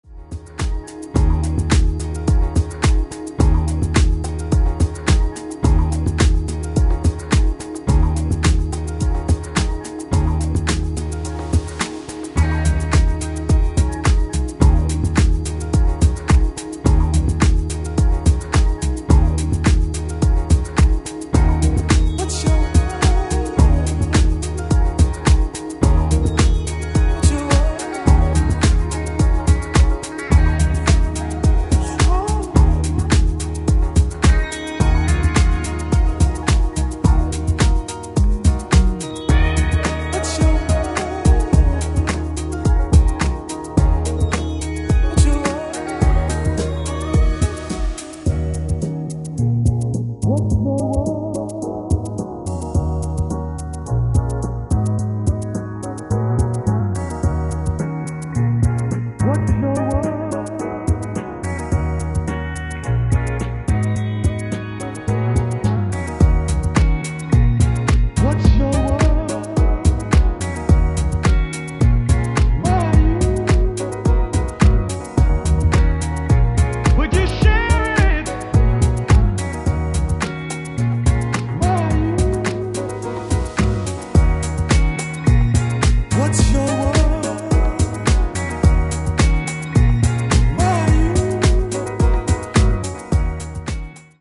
4 cuts of sub 120 boogie bliss.